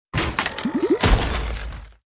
greedybox_openbox.mp3